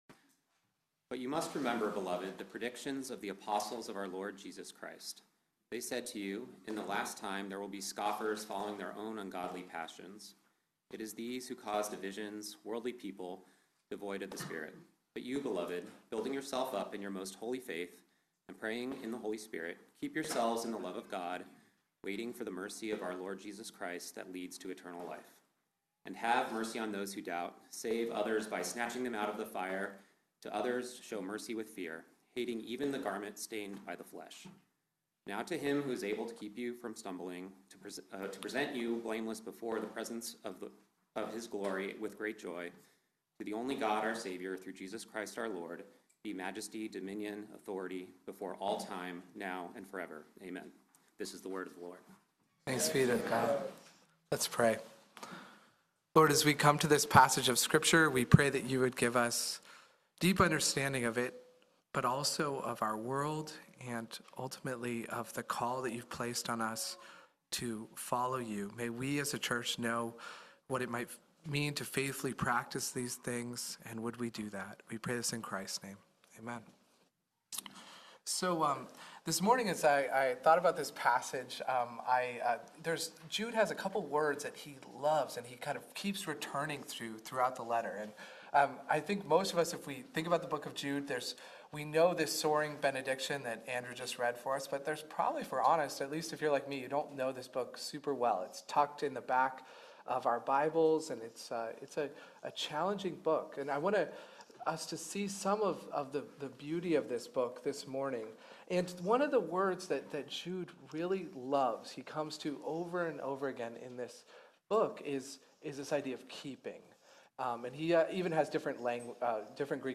by Trinity Presbyterian Church | Mar 27, 2026 | Sermon